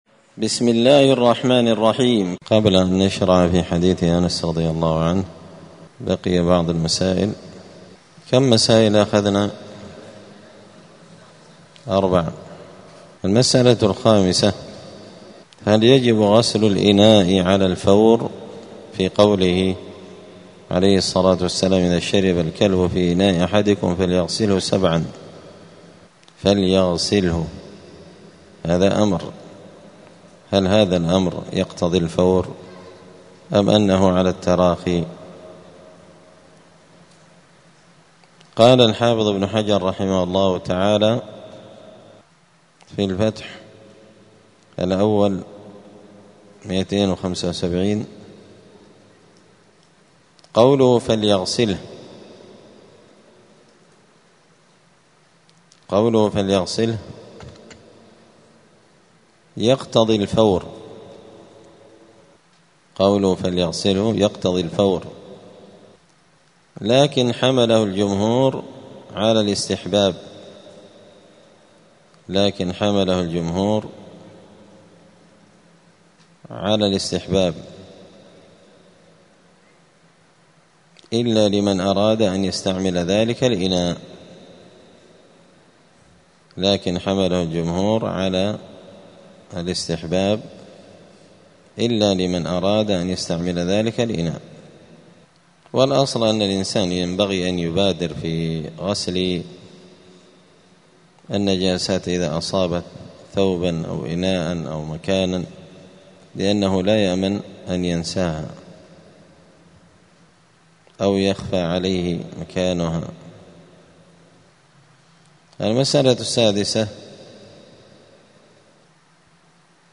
دار الحديث السلفية بمسجد الفرقان قشن المهرة اليمن
*الدرس السادس عشر بعد المائة [116] {باب إزالة النجاسة هل يجب غسل الإناء على الفور}*